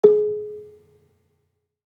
Gamelan Sound Bank
Gambang-G#3-f.wav